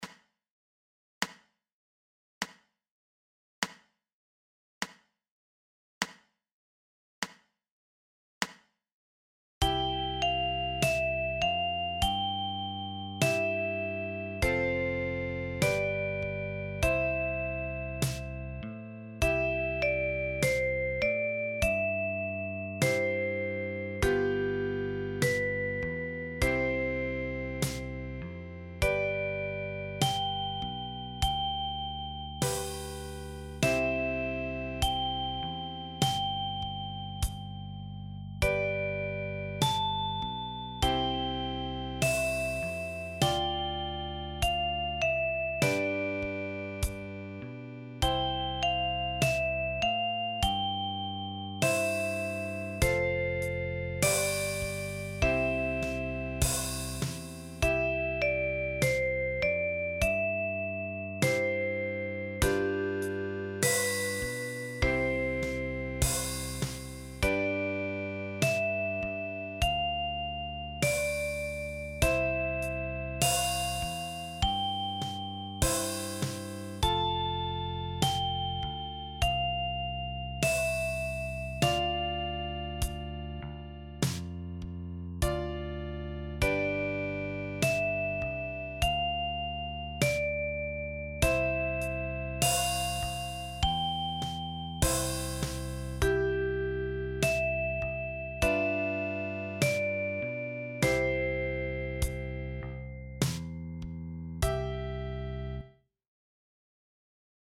Für Altblockflöte in F.